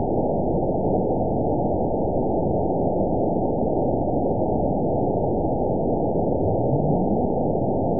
event 920451 date 03/26/24 time 02:26:30 GMT (1 year, 1 month ago) score 9.51 location TSS-AB02 detected by nrw target species NRW annotations +NRW Spectrogram: Frequency (kHz) vs. Time (s) audio not available .wav